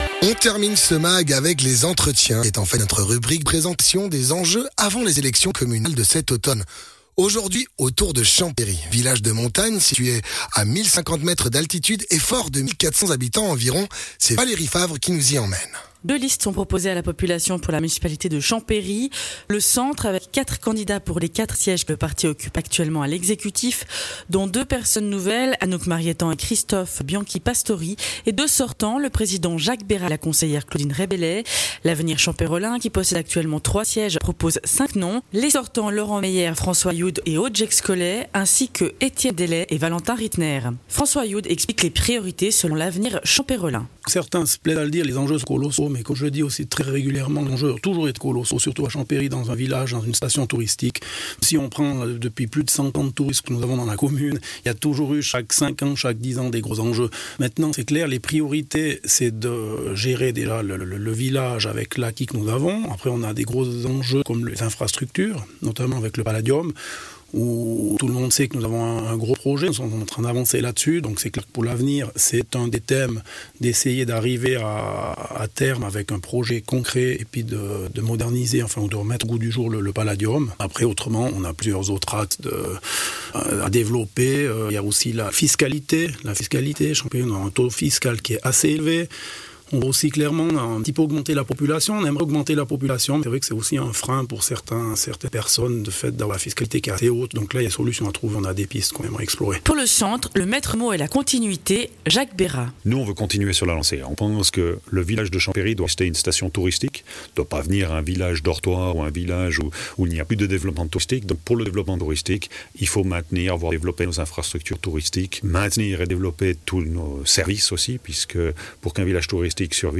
Entretien: les enjeux de Champéry